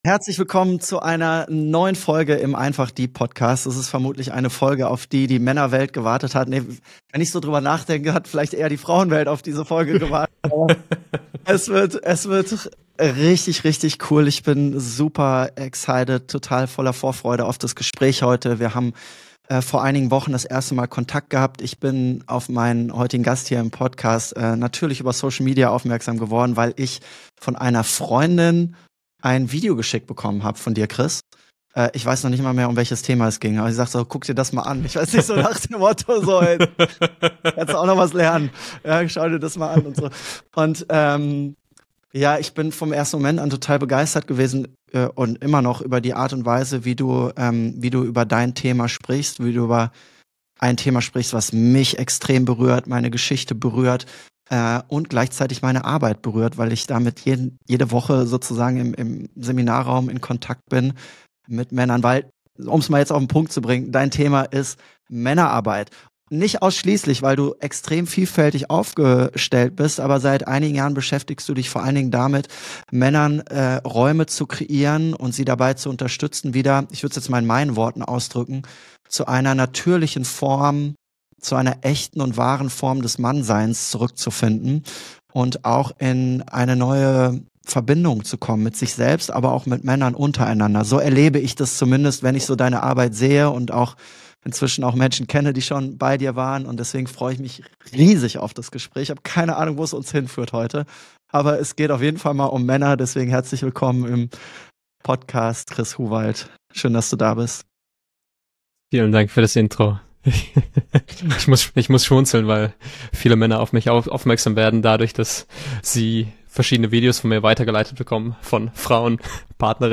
Dieses Gespräch ist ehrlich, direkt und tief.